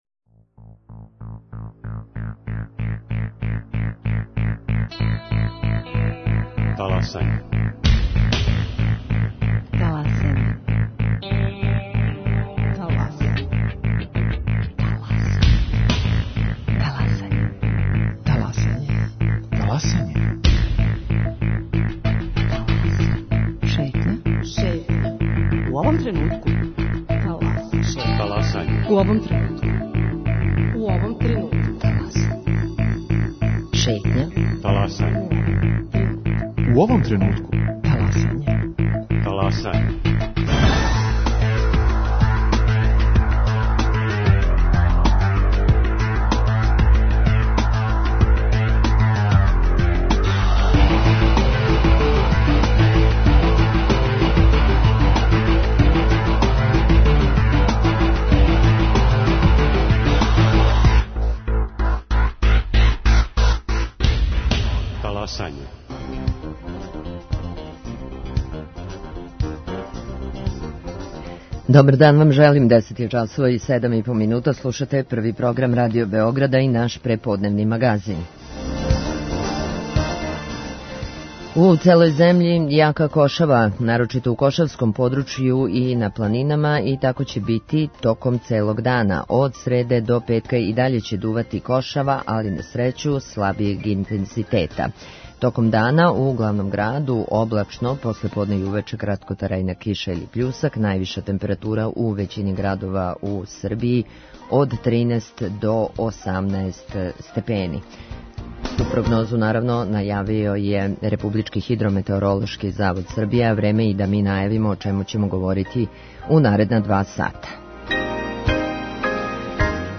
Гост је професор са Економског факултета и бивши гувернер НБС Дејан Шошкић.